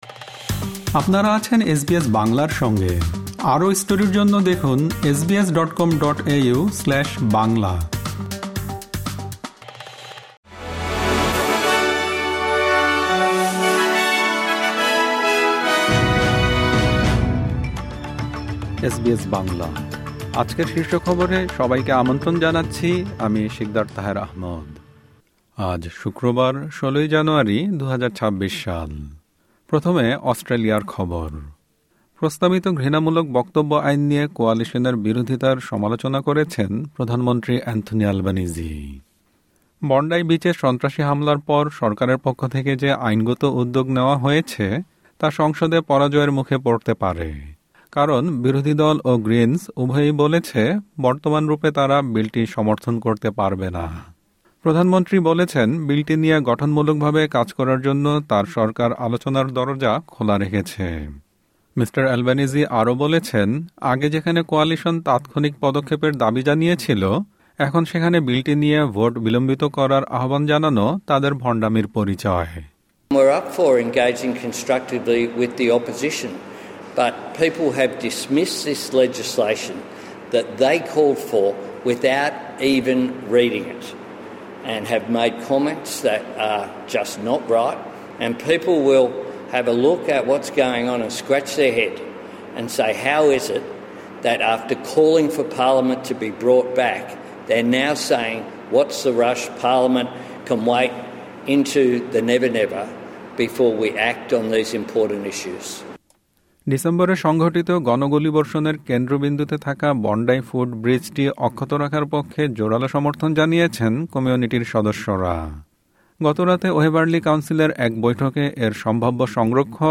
এসবিএস বাংলা শীর্ষ খবর: হেট স্পিচ আইন পরিবর্তনের বিরোধিতা করায় কোয়ালিশনের বিরুদ্ধে ভণ্ডামির অভিযোগ